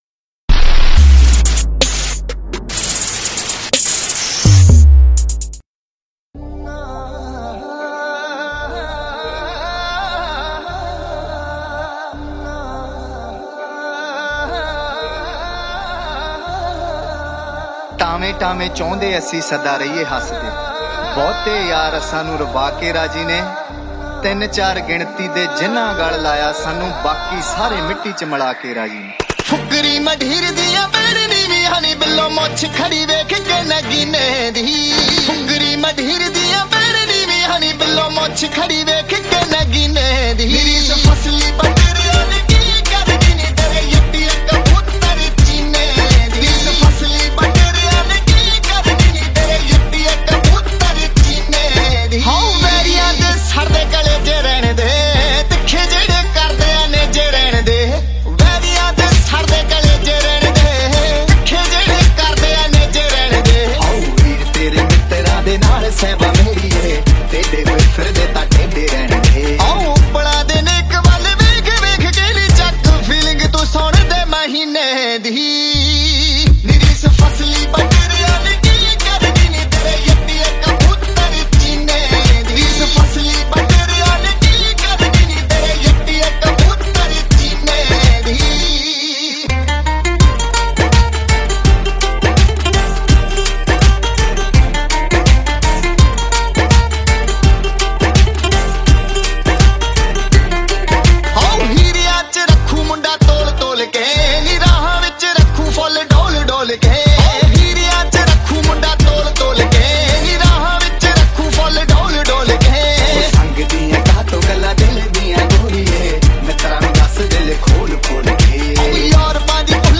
Punjabi Mp3 Songs